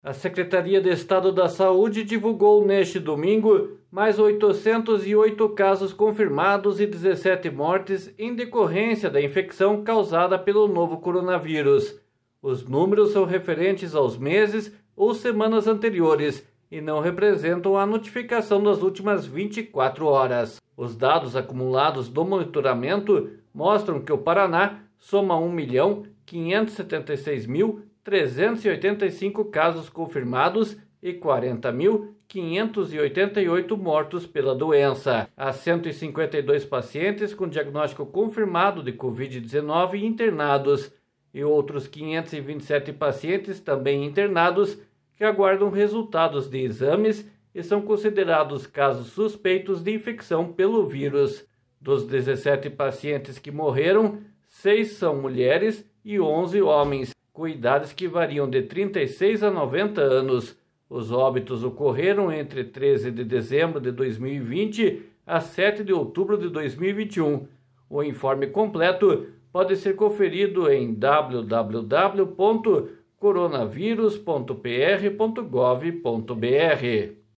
BOLETIM COVID.mp3